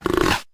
sounds_leopard_snort.ogg